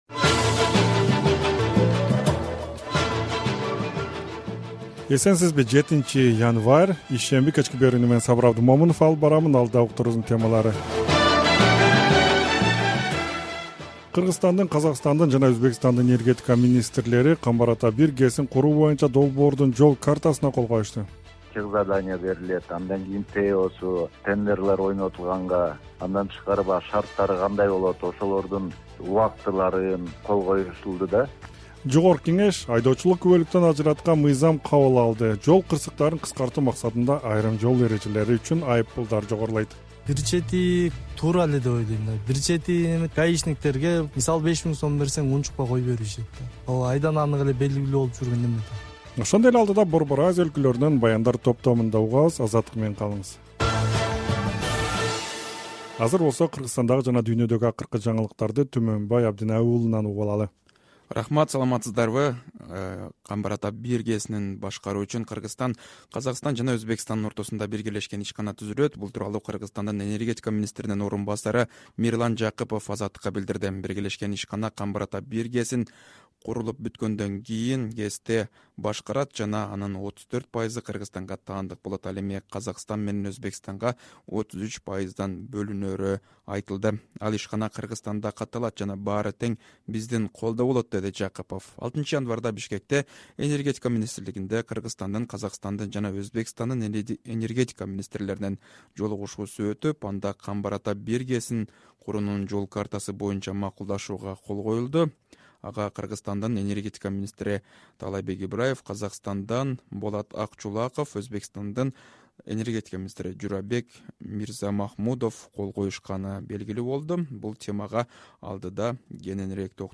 Кечки радио берүү | 07.01.2023 | “Камбар-Ата-1” ГЭСин үч өлкө курмай болду